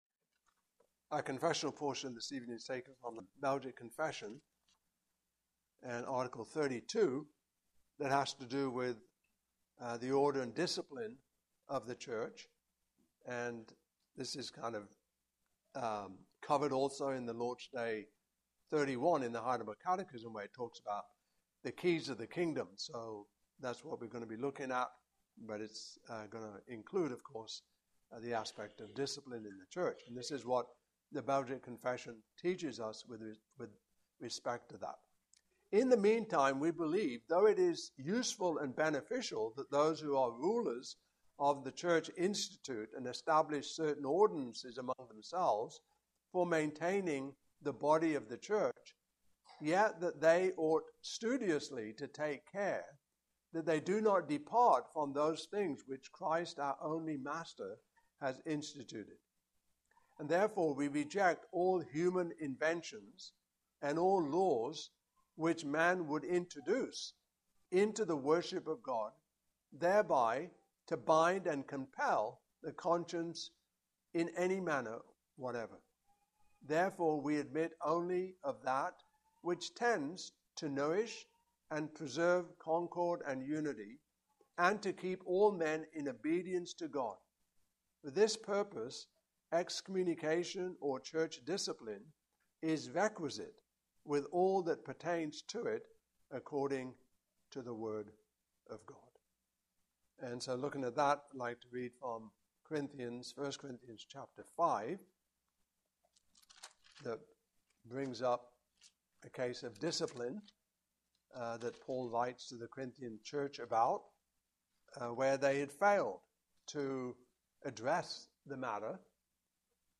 Passage: I Corinthians 5:1-13; 2 Corinthians 2:1-17 Service Type: Evening Service